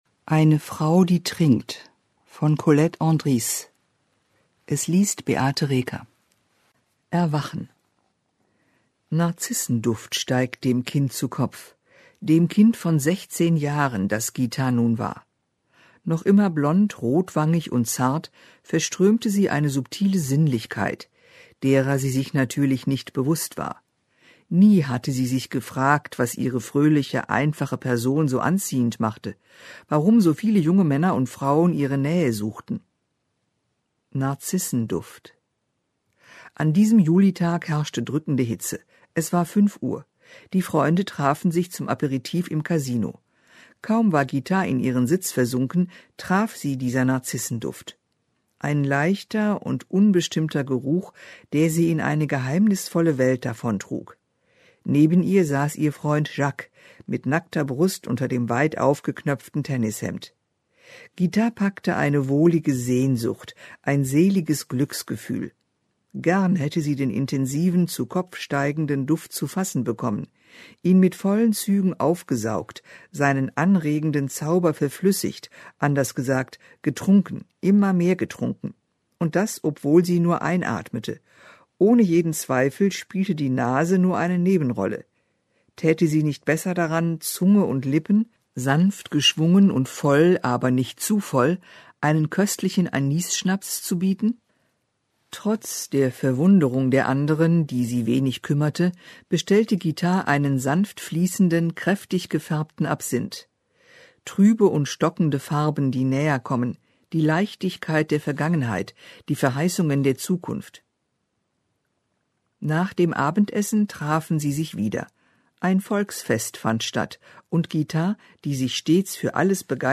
Diesen berührenden Roman liest für Sie